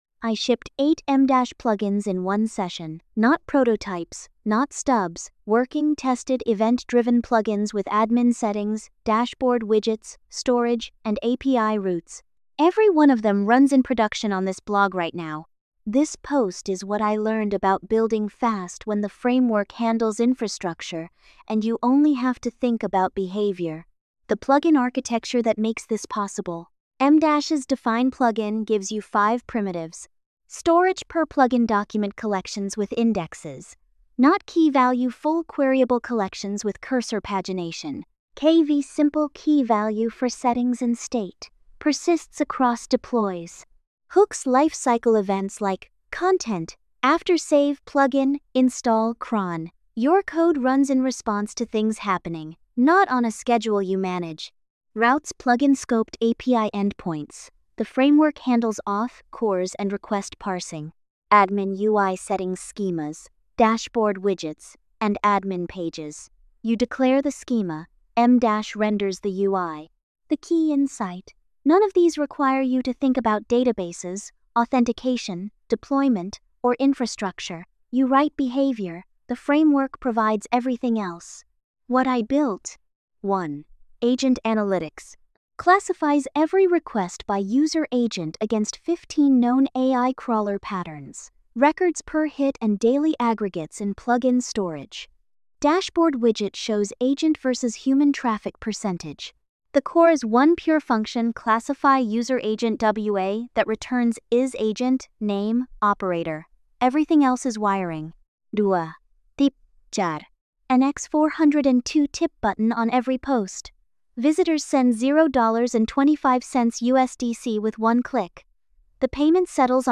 AI-narrated with MiniMax speech-2.8-hd · 6:45.